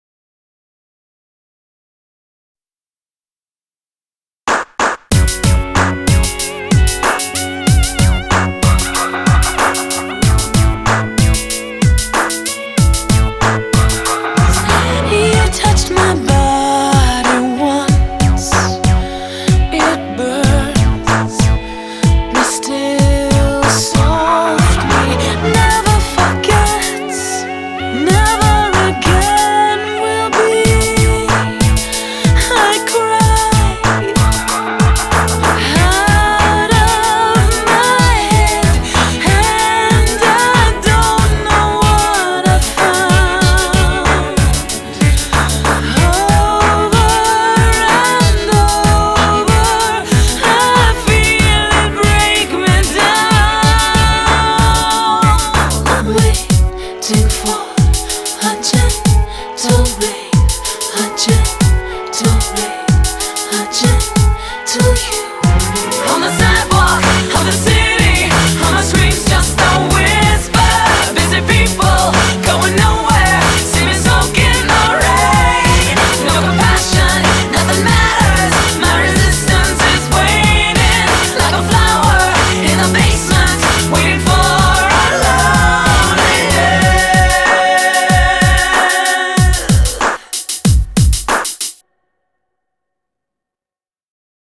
BPM84
Audio QualityPerfect (High Quality)